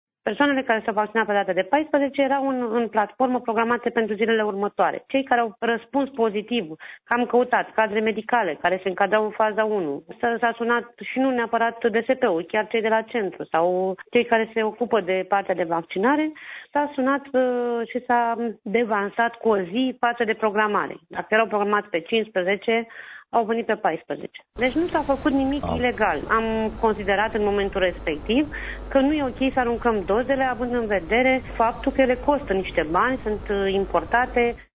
Explicația a fost oferită de directorul DSP Botoșani, Monica Adăscăliței, într-o intervenție pentru Radio România Iași.